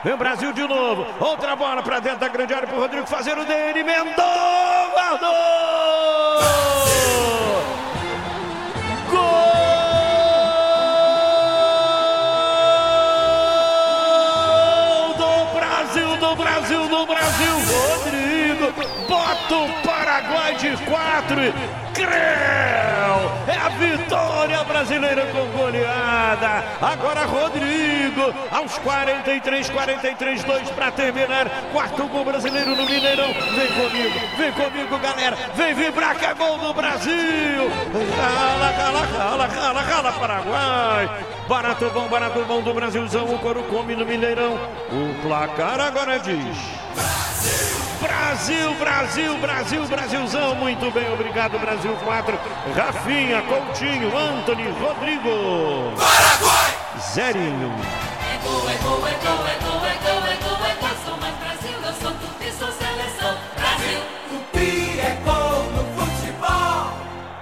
Ouça os gols da goleada do Brasil sobre o Paraguai pelas Eliminatórias com a narração de Luiz Penido